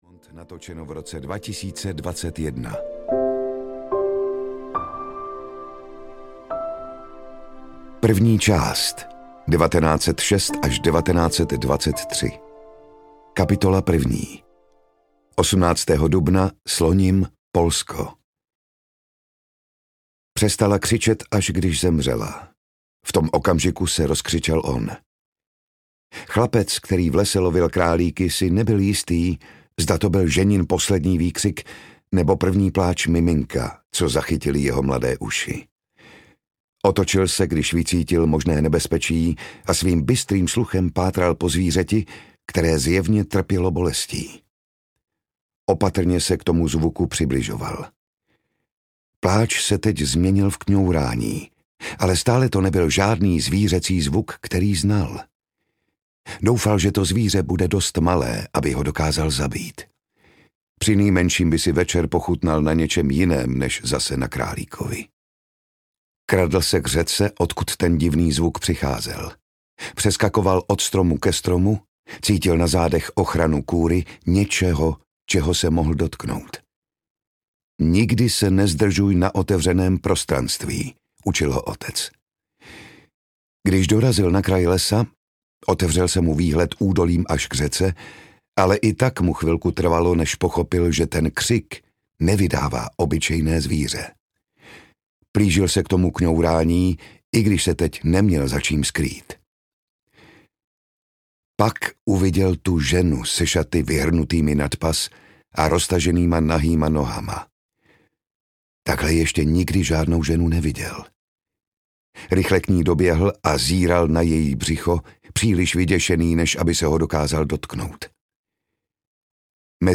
Kane a Abel audiokniha
Ukázka z knihy
• InterpretJan Šťastný